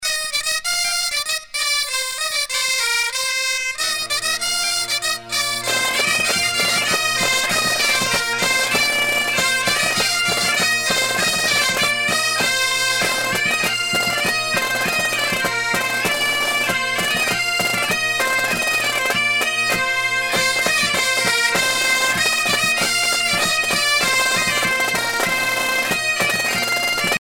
Danse du Pays Vannetais
Pièce musicale éditée